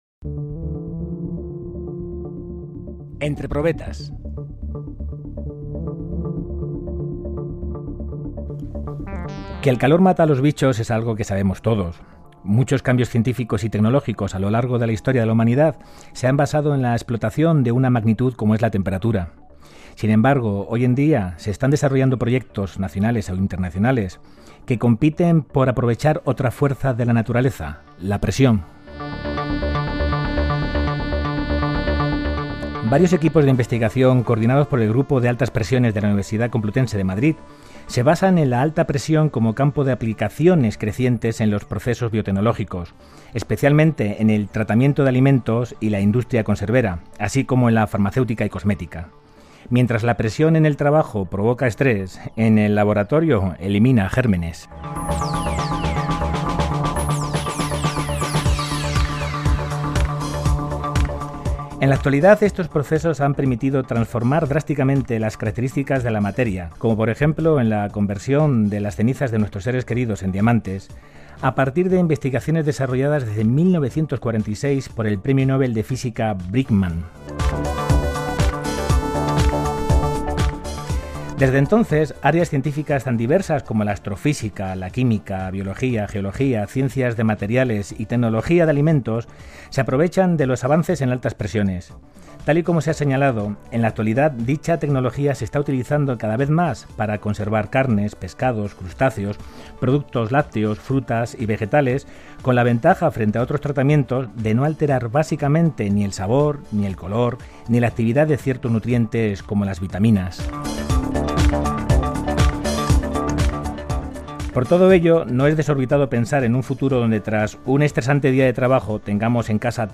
Entre Probetas describe, en apenas dos minutos, y en el tono desenfadado y directo, lo m?s destacado de la biolog?a -en todas sus facetas-, medicina,tecnolog?a o cualquier otra rama de la investigaci?n humana